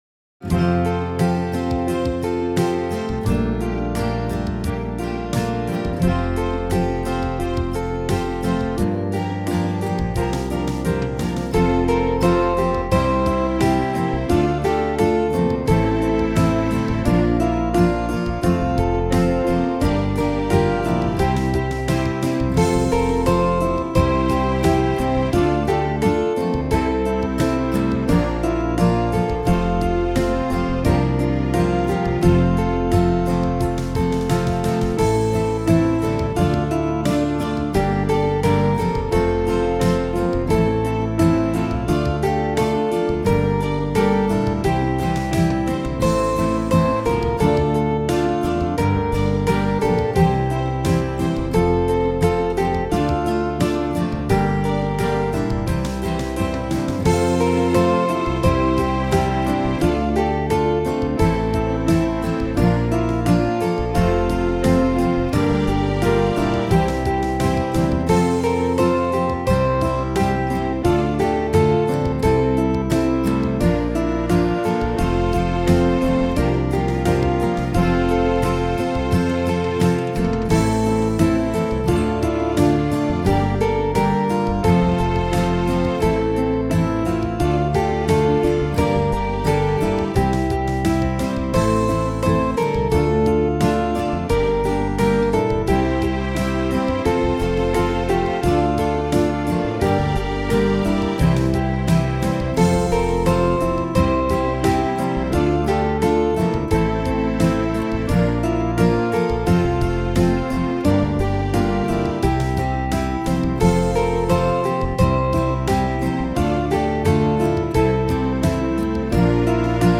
My backing is pretty obviously mostly MIDI.